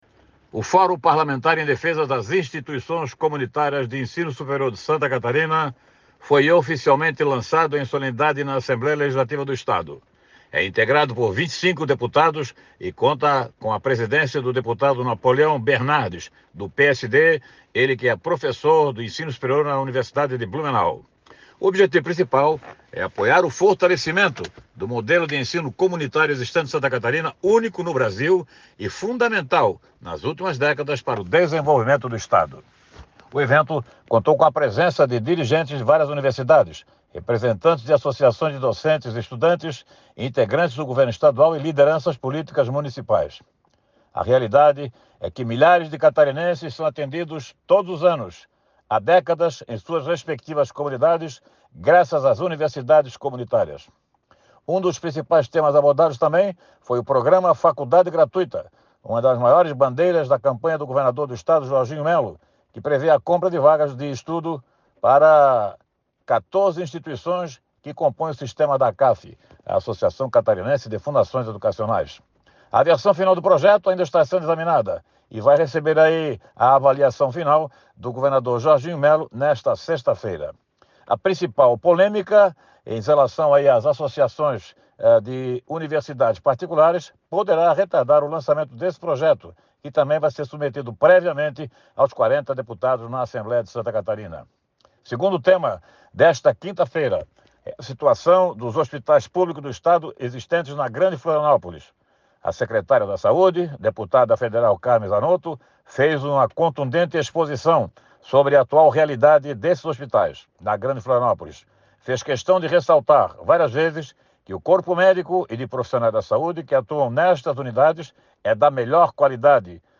Confira o comentário na íntegra